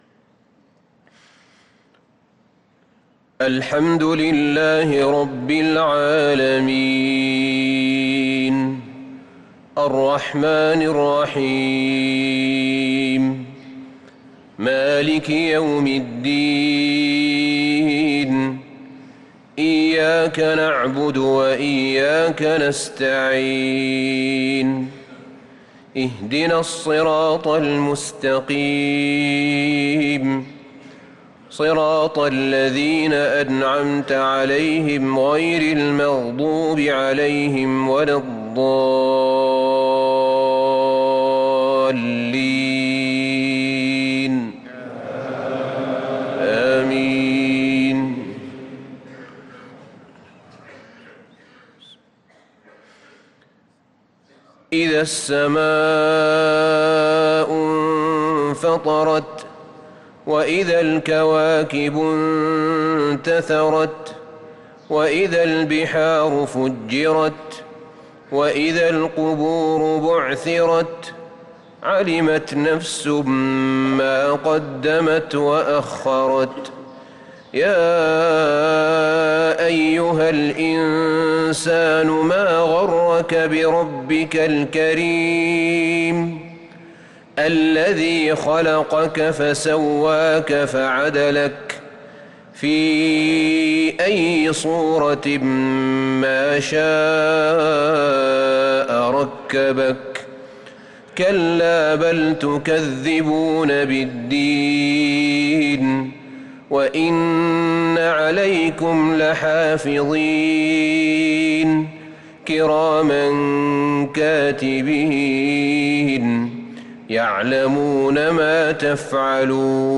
صلاة المغرب للقارئ أحمد بن طالب حميد 12 ربيع الآخر 1444 هـ
تِلَاوَات الْحَرَمَيْن .